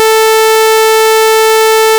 Als Schallschwingung klingt eine Sägezahnschwingung so
Sawtooth-440Hz.ogg